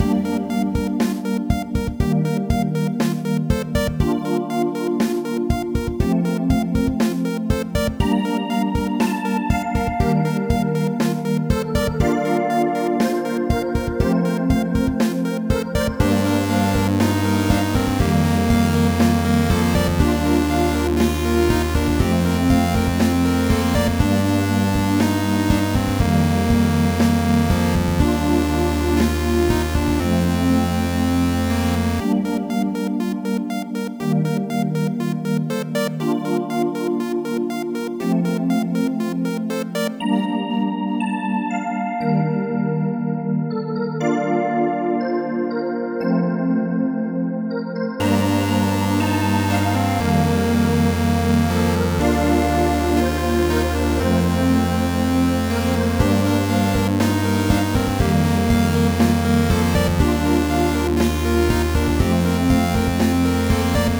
A upbeat spac-ish theme.